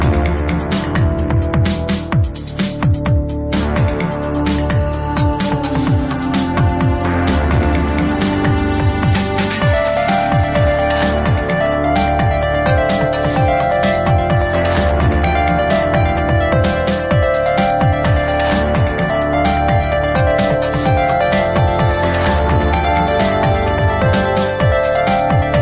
very nice progressive track PLEASE ID
i thought it was very nice, something i could space out to...